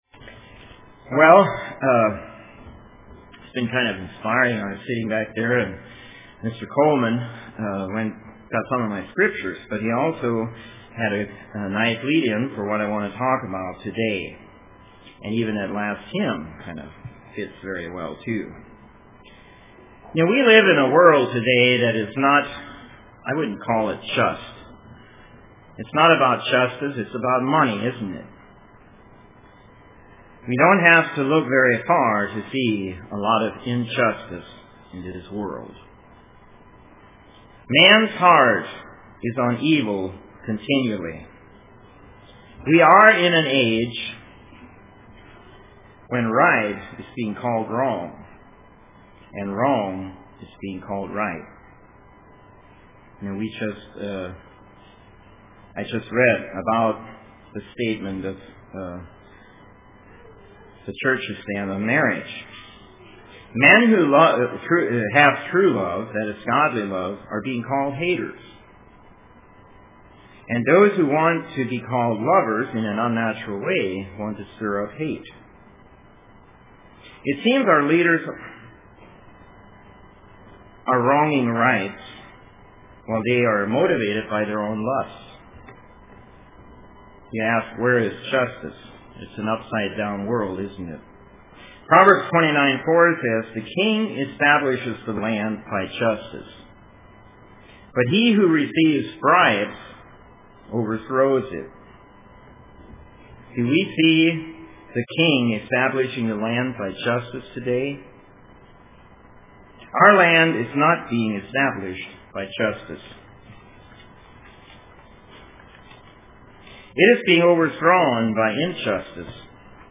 Print God's Law of Love UCG Sermon